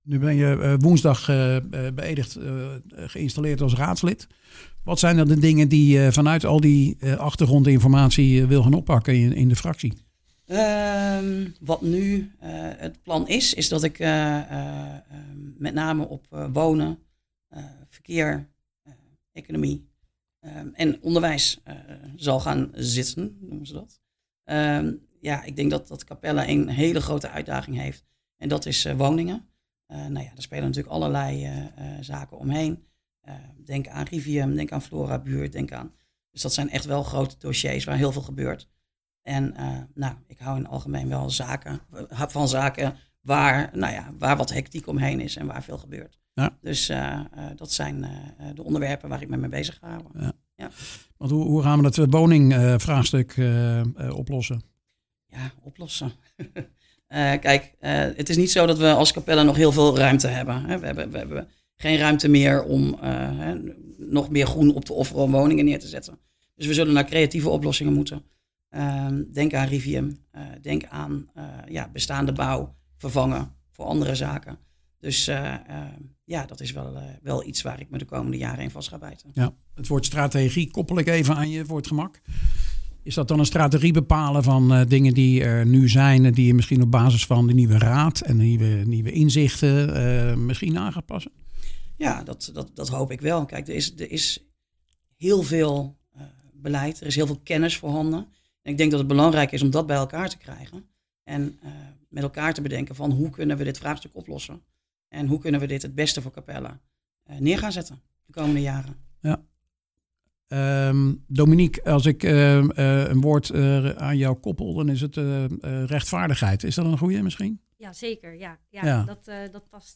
Dit is deel 2 van dat gesprek over wat zij gaan doen in de raad binnen de fractie. Over de vormen van veiligheid, cameratoezicht, preventief fouilleren, messen en meer resultaat vanuit de raad.